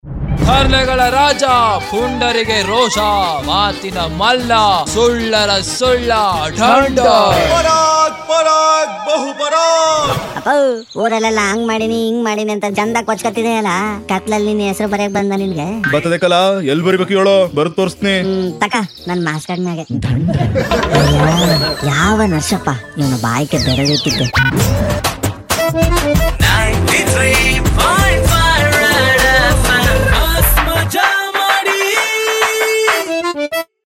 Most Loved Comedy Audio Clip That Makes YOU ROFL!!!